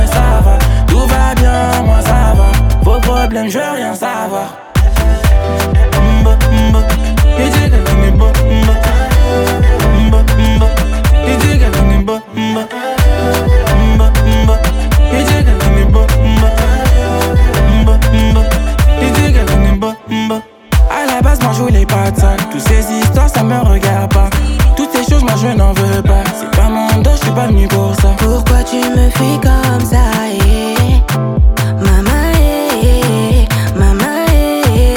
Жанр: Поп / Африканская музыка
# Afro-Pop